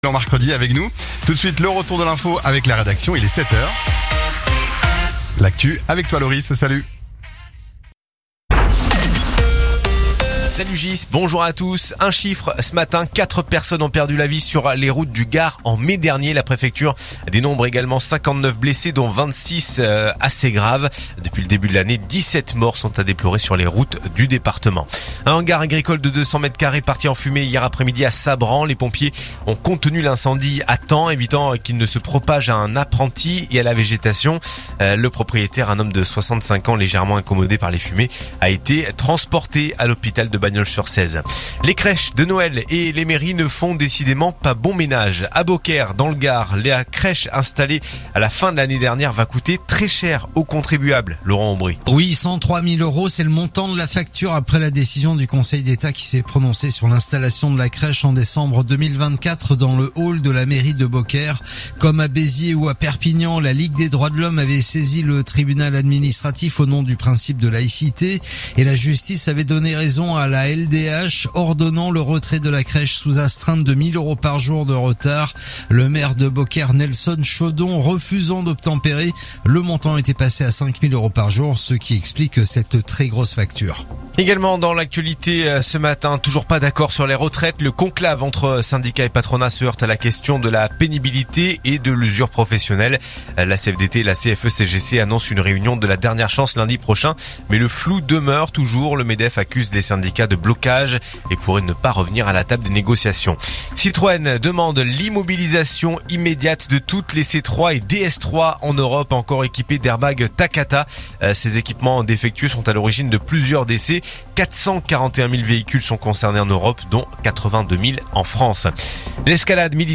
info_nimes_418.mp3